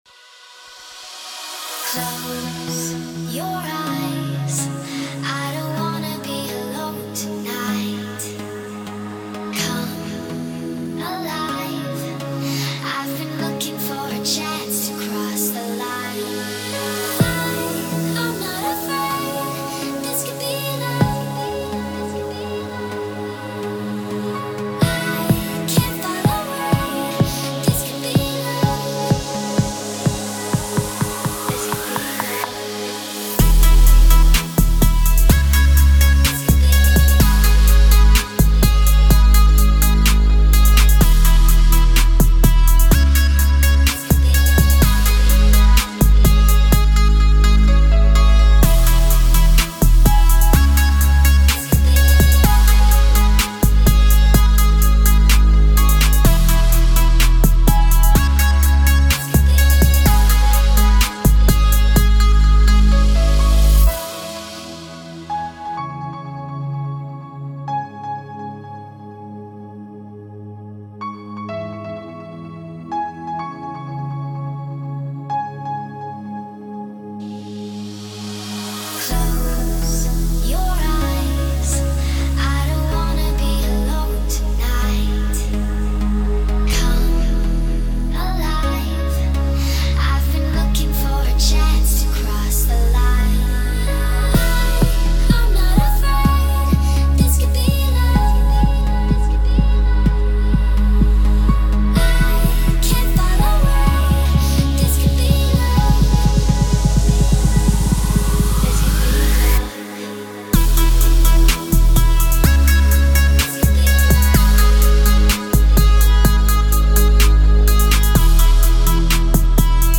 Бас в авто